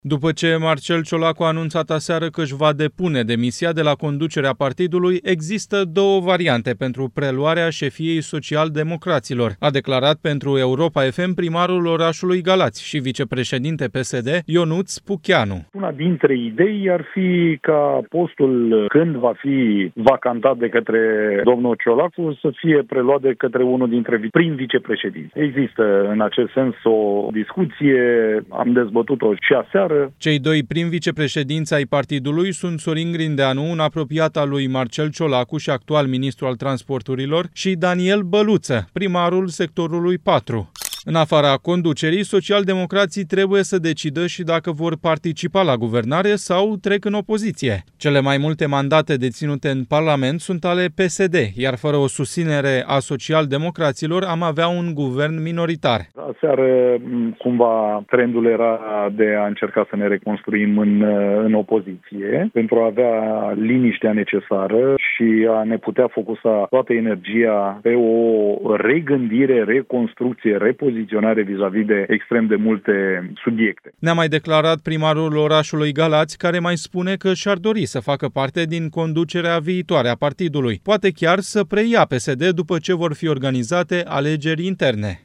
După ce Marcel Ciolacu a anunțat aseară că își va depune demisia de la conducerea partidului, există două variante pentru preluarea șefiei social-democraților, a declarat pentru Europa FM primarul orașului Galați – și vicepreședinte PSD – Ionuț Pucheanu.